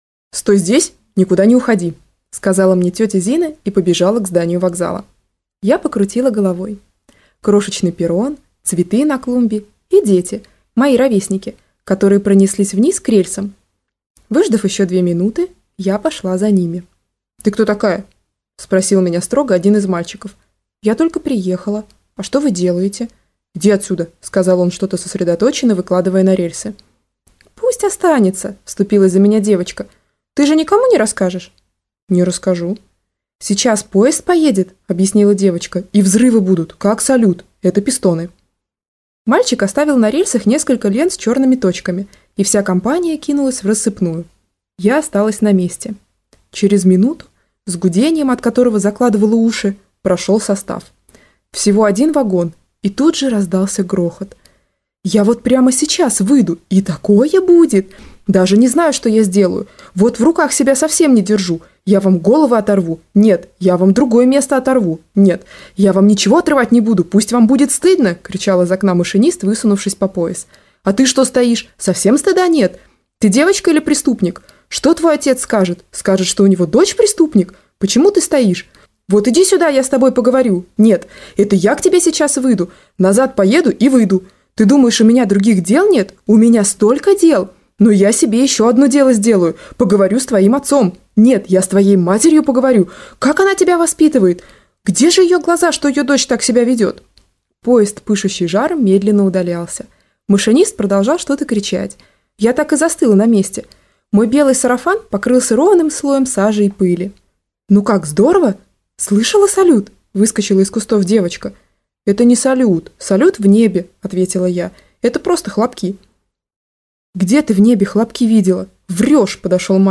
Аудиокнига Наша девочка - купить, скачать и слушать онлайн | КнигоПоиск